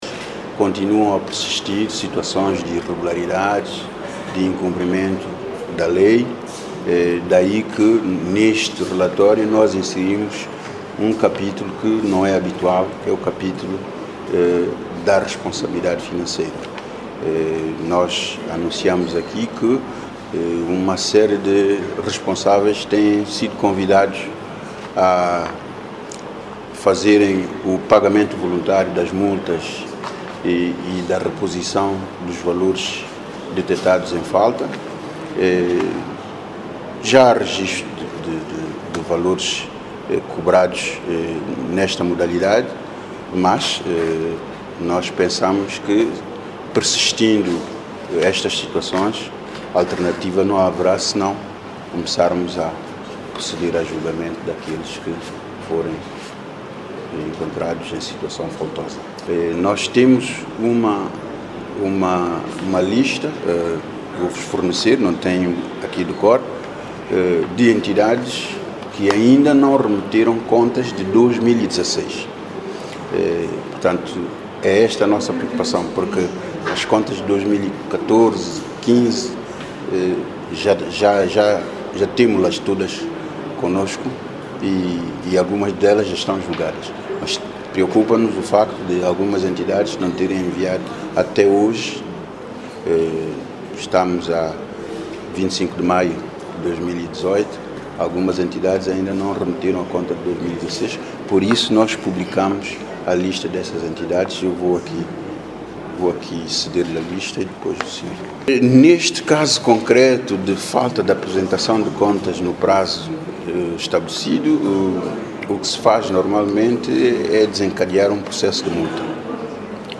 “Nós [Tribunal de Contas] pensamos que persistindo estas situações de incumprimento, alternativa não haverá senão começarmos a proceder aos julgamentos daqueles que forem encontrados em situação faltosa”- sublinhou Monte Cristo em declarações a imprensa.
Declaração Presidente Tribunal de Contas, José António M. Cristo